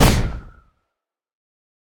wind_burst1.ogg